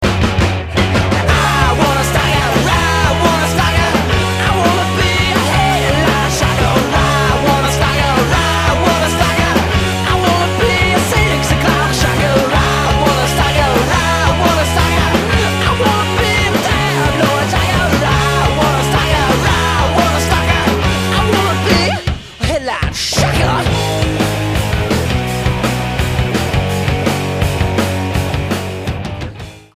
STYLE: Rock
punk rock band